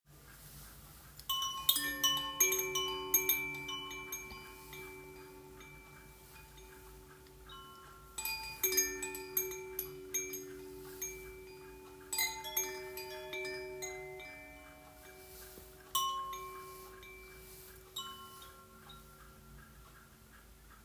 Windgong windchime Hluru chime C akkoord
De windchime die je hebt opgehangen aan een tak van de boom in je achtertuin klinkt zacht en warm.
• Warm en zacht geluid
• Natuurlijke klanken
• Wordt bewogen door de wind